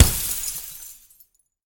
breakglass.ogg